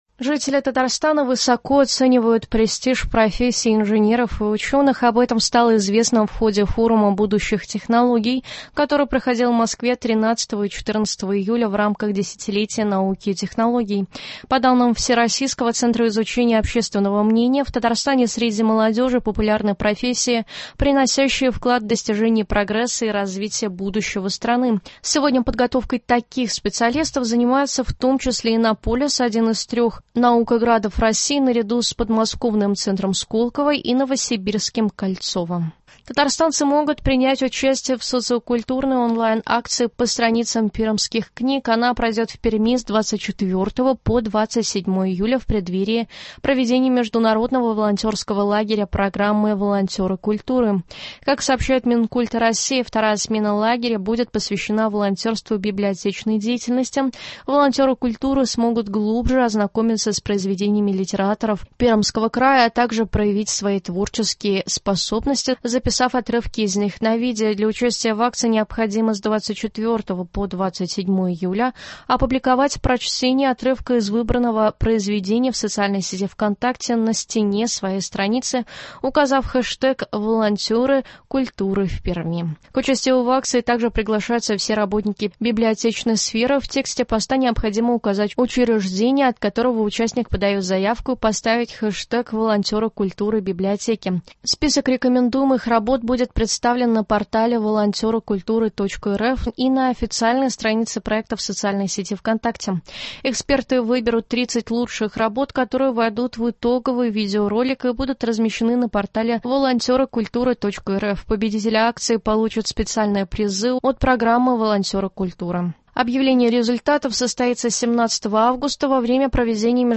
Новости (17.07.23)